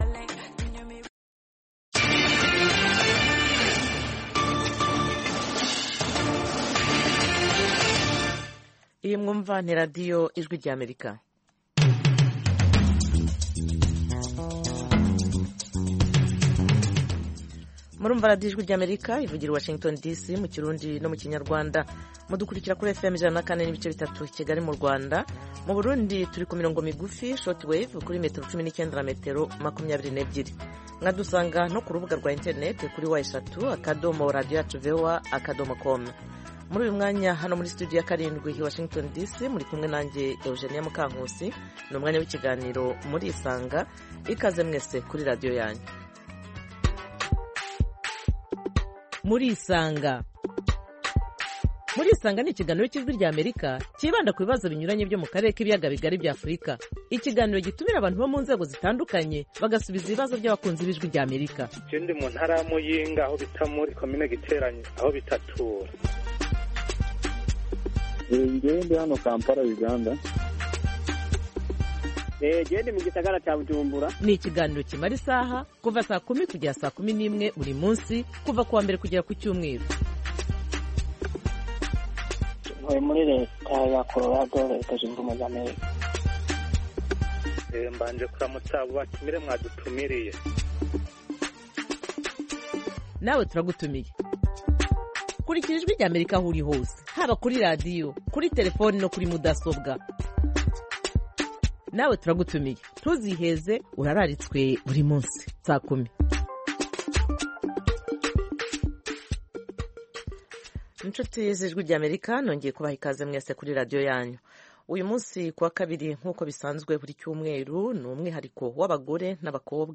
Ikiganiro Murisanga co kuri uyu wa kabiri kiribanda ku ruhare rw’ababyeyi bombi mu burere bw’abana mu miryango. Umutumire ni umunyarwandakazi